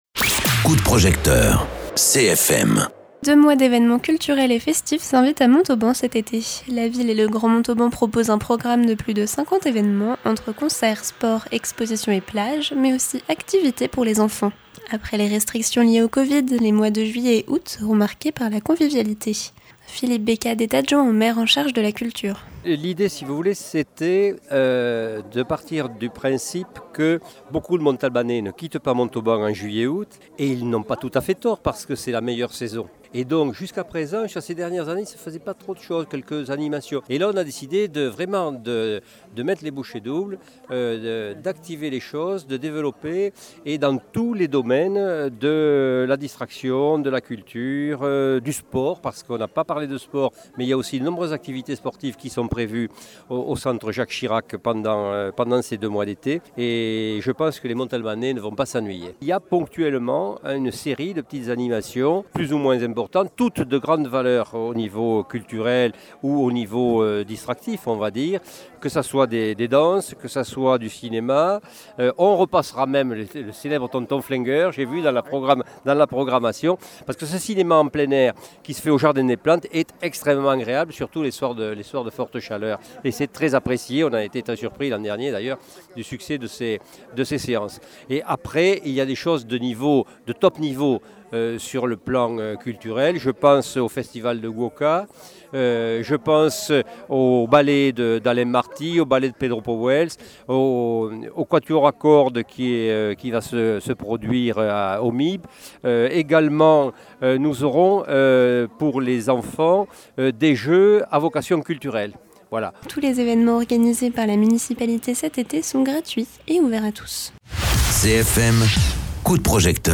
Invité(s) : Philippe Becade, ajoint au maire de Montauban en charge de la culture